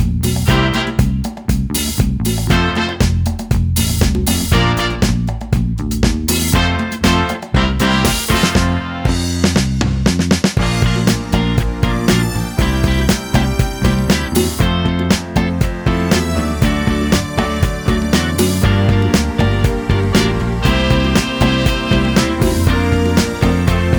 no bass guitar Soul / Motown 2:59 Buy £1.50